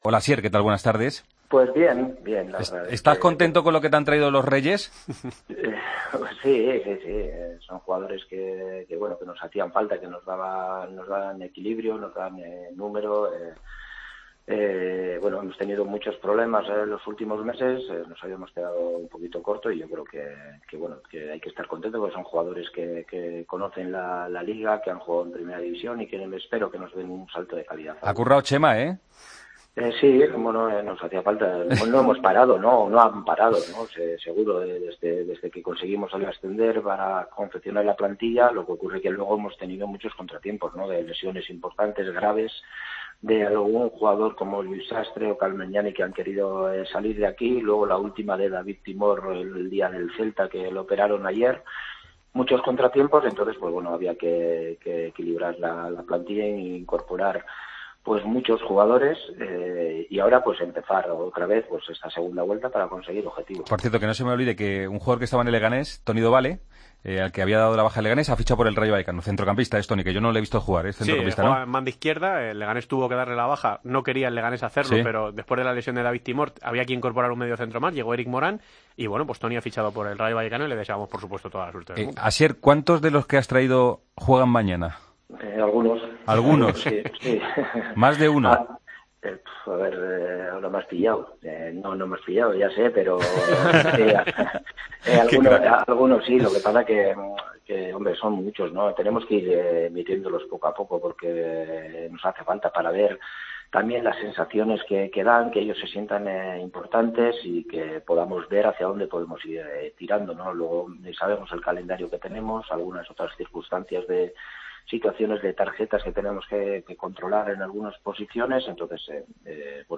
El entrenador del Leganés pasó por el micrófono de COPE en la previa de la visita de su equipo al Vicente Calderón. Garitano prometió que su equipo dará guerra afirmando que "antes de jugar el partido tienes que pensar que puedes ganar, si no, es imposible".